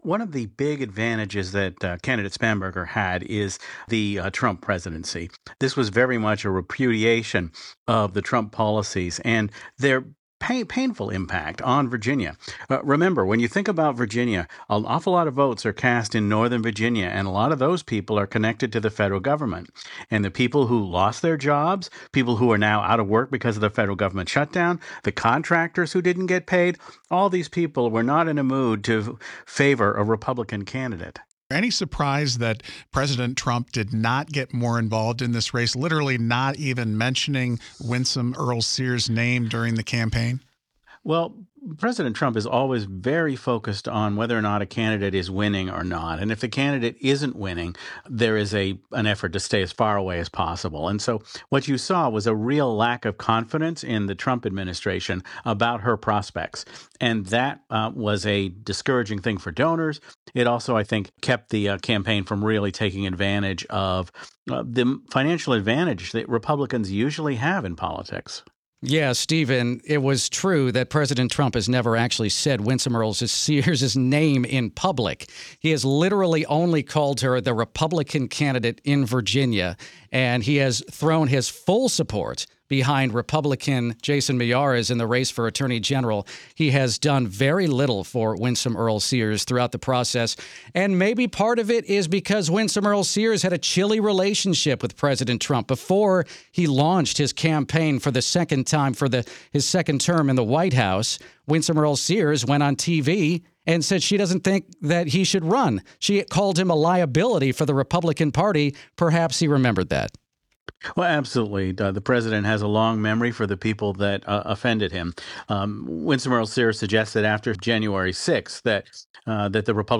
joined WTOP to recap the Virginia gubernatorial race.